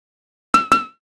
fire-1.ogg